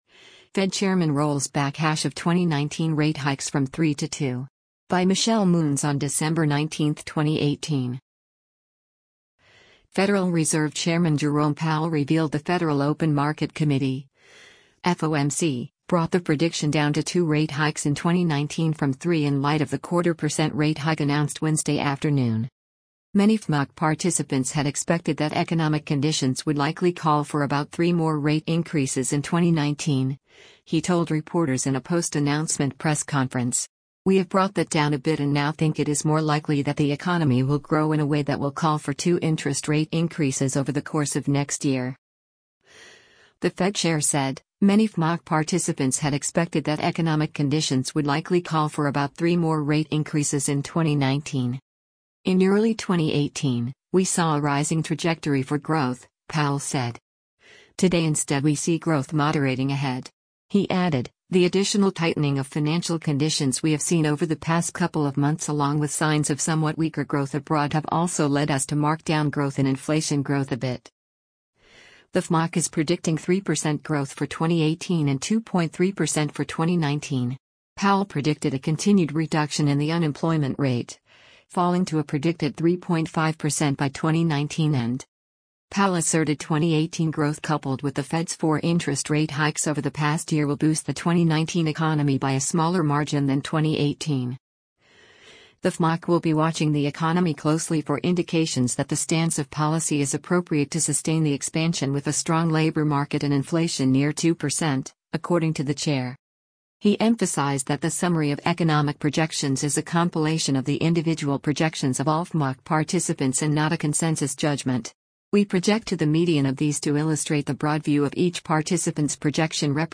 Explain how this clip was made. “Many FMOC participants had expected that economic conditions would likely call for about three more rate increases in 2019,” he told reporters in a post-announcement press conference.